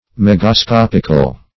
Megascopic \Meg`a*scop"ic\, Megascopical \Meg`a*scop"ic*al\, a.